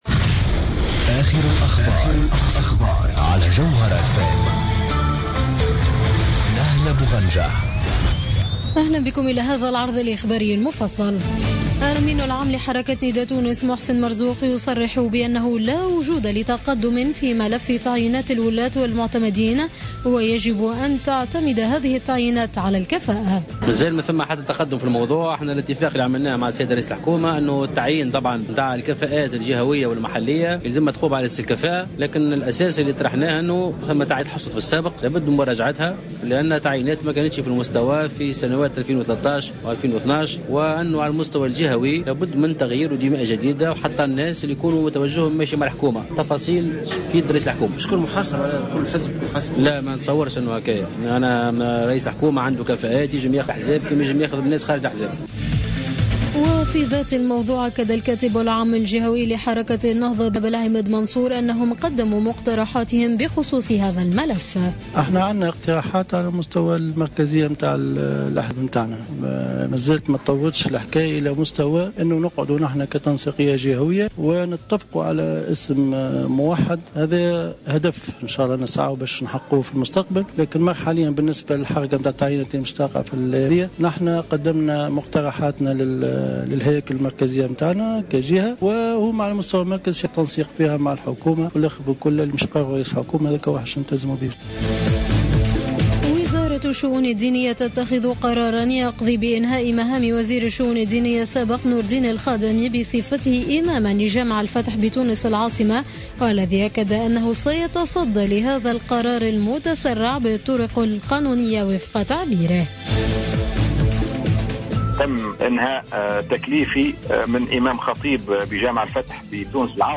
نشرة أخبار منتصف الليل ليوم الأحد 09 أوت 2015